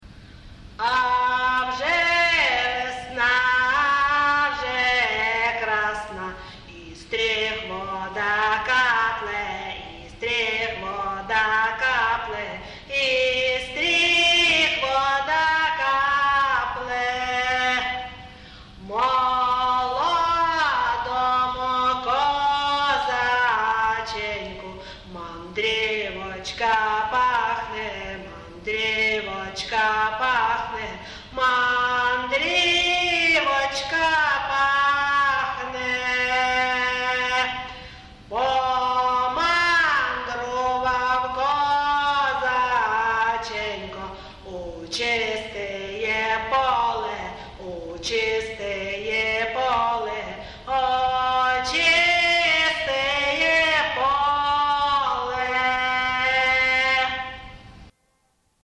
ЖанрВеснянки
Місце записус. Ковалівка, Миргородський район, Полтавська обл., Україна, Полтавщина